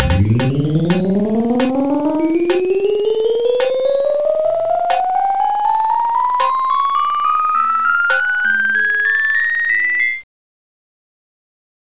This is an example of the square root of X. This graph is presented in both pictorial, and auditory formats. In the auditory graph, the Y-Axis is represented by Pitch, and the X-Axis is represented by time. First and second derivative information (slope and curvature) about graphed data is represented with a drum beat, the rate of the beat represents the slope, and the pitch represents positive, 0, or negative curvature.